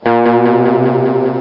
Guitar Br Sound Effect
guitar-br.mp3